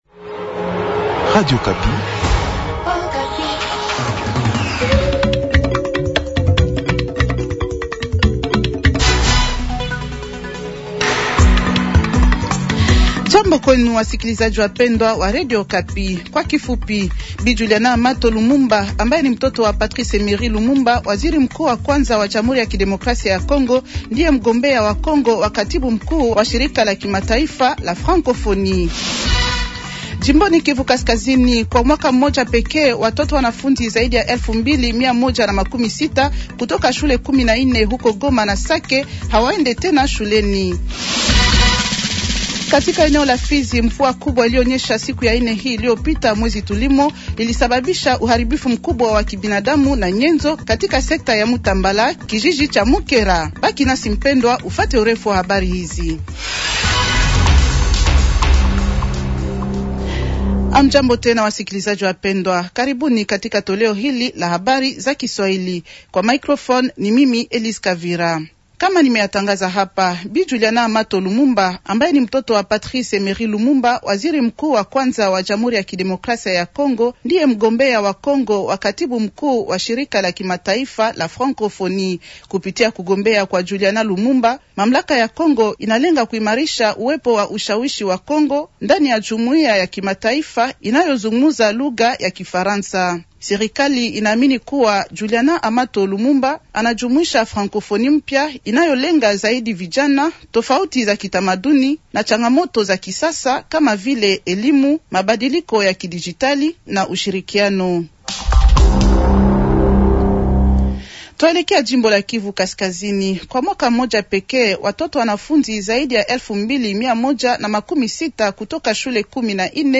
Journal Swahili de vendredi soir 270226